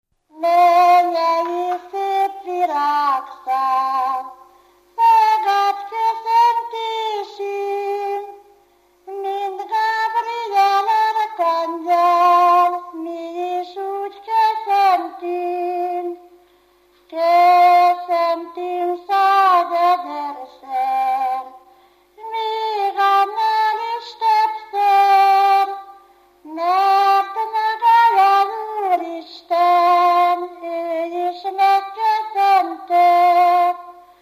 Alföld - Pest-Pilis-Solt-Kiskun vm. - Galgahévíz
ének
Stílus: 8. Újszerű kisambitusú dallamok
Szótagszám: 7.6.7.6
Kadencia: V (2) 1 1